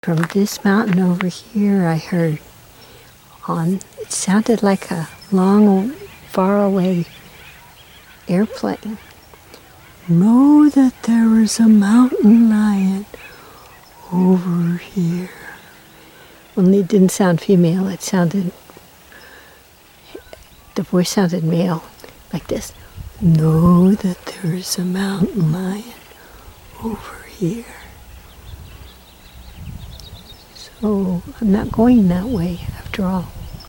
I am in Malibu Creek State Park, and I started going up Mott Road towards Salvation Army.
What the Mountain Lion Sounded Like
… I heard … It sounded like a far-away airplane …